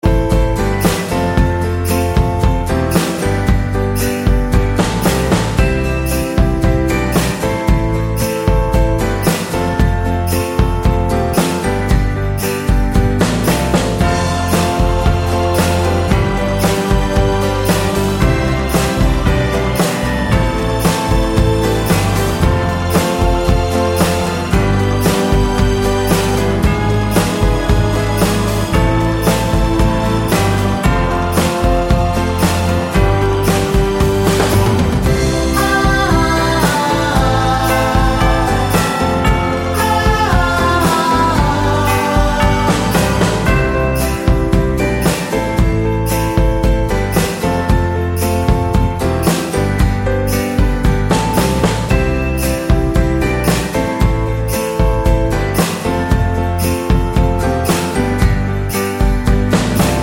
Duet Version Christmas 3:27 Buy £1.50